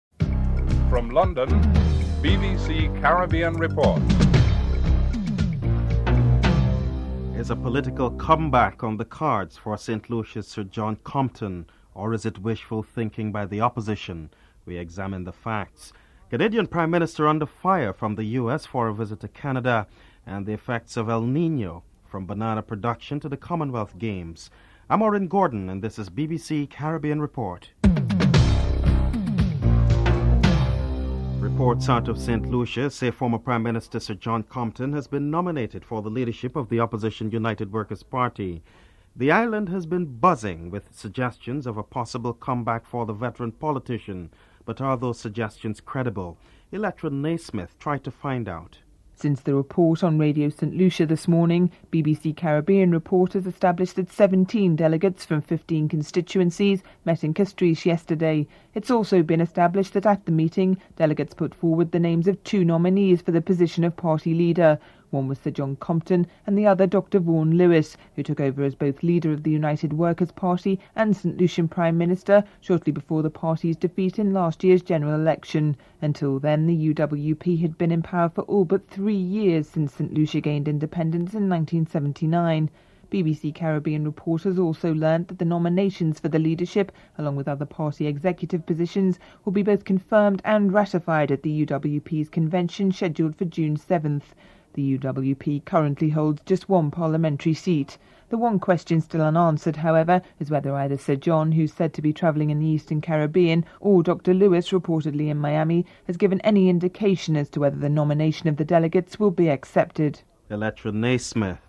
The Commonwealth Games scheduled to take place in Malaysia in four months time is under threat from adverse weather caused by El Nino. The Malaysian Prime Minister Mahathir Mohamad comments on the issue (12:15-15:10)